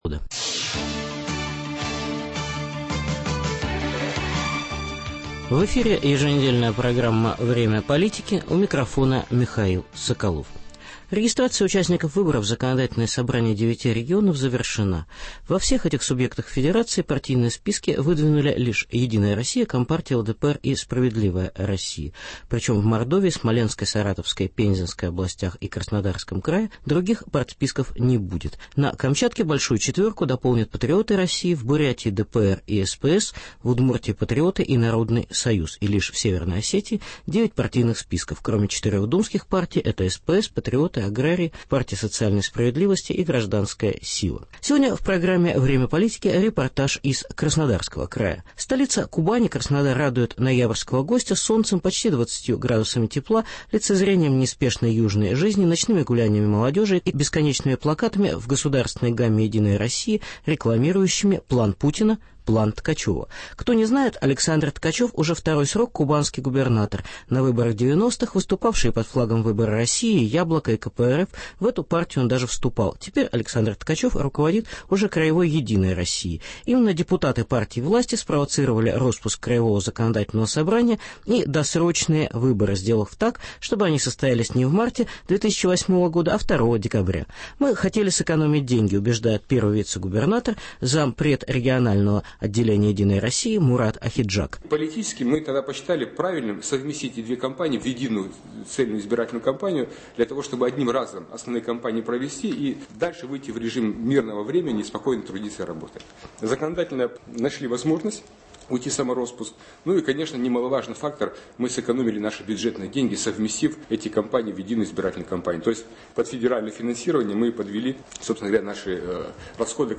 Специальный репортаж о выборах в Краснодарском крае.